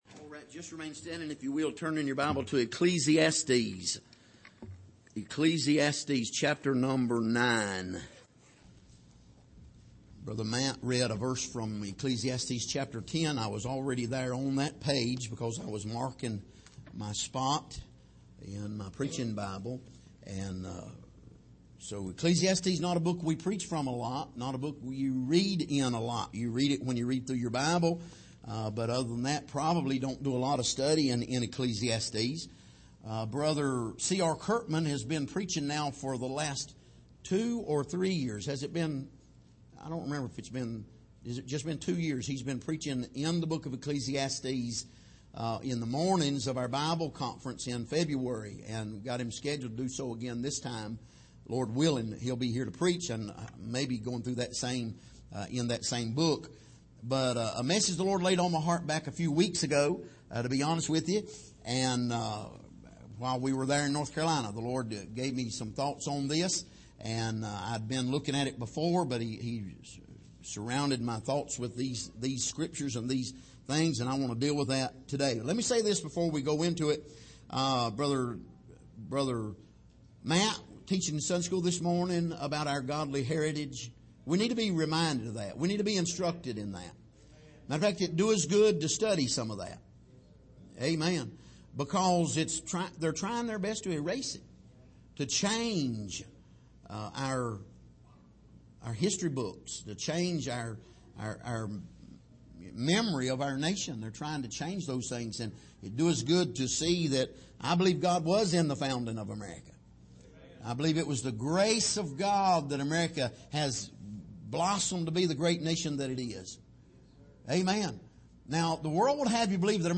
Passage: Ecclesiastes 9:3 Service: Sunday Morning